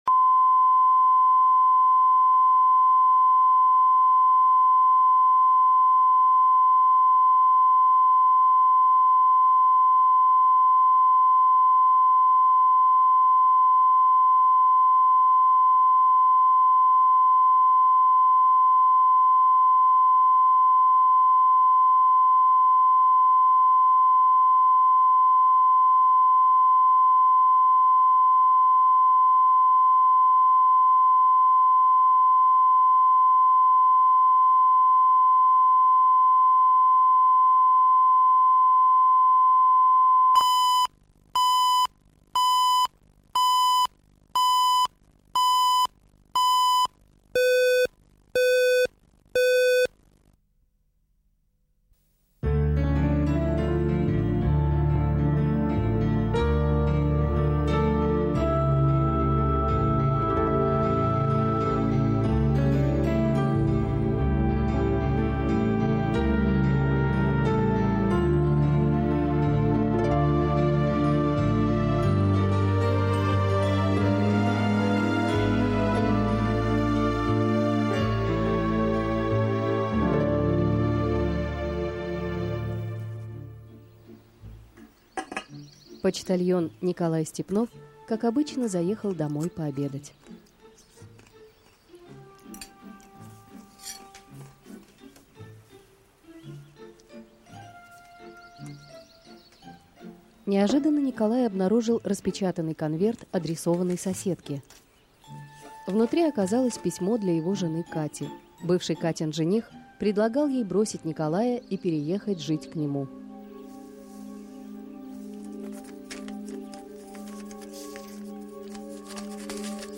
Аудиокнига Письма нежные | Библиотека аудиокниг
Прослушать и бесплатно скачать фрагмент аудиокниги